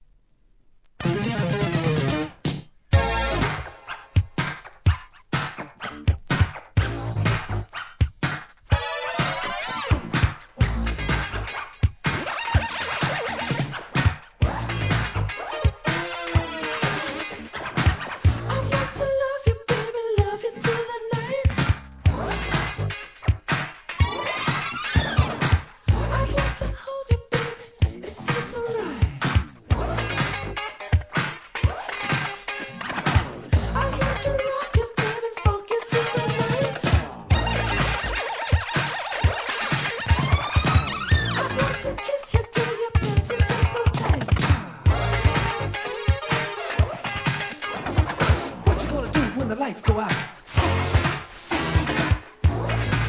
HIP HOP/R&B
エレクトロ怒FUNKトラックでのRAP!!